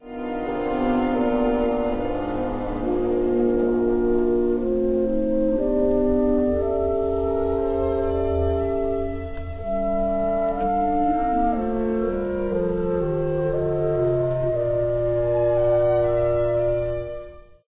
Berg_vn_conc_clarinet_chorale.ogg